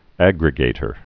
(ăgrĭ-gātər)